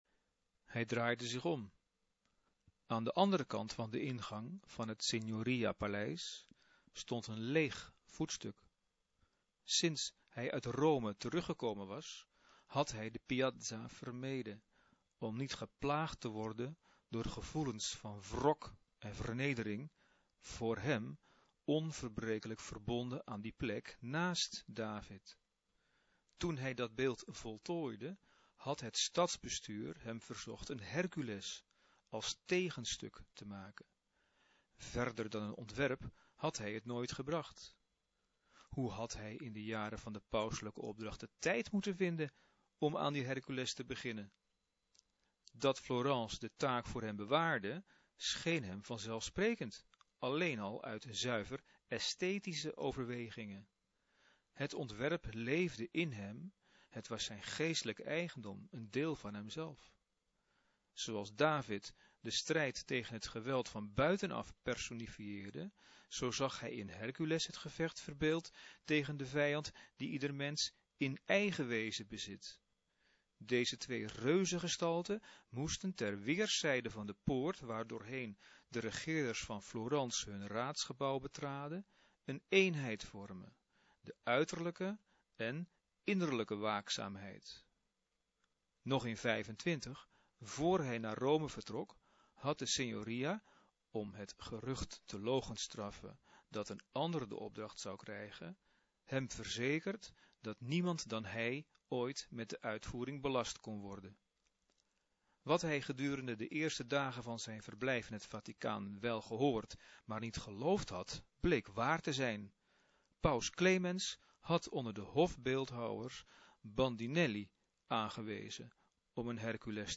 Betekent: het fragment wordt voorgelezen. (MP-3)